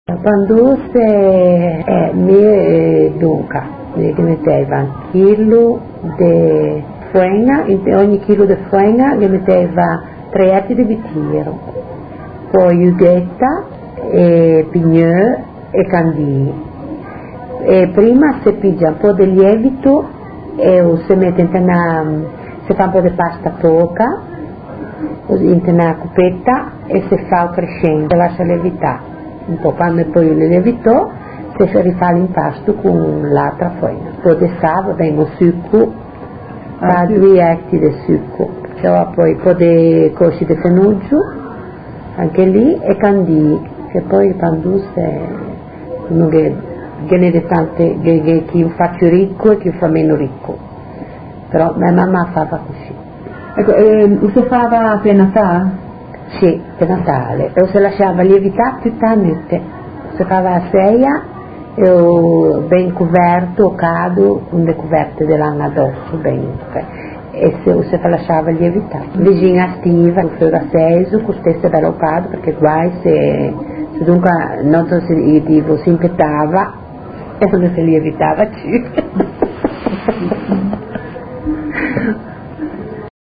Alta Valle Scrivia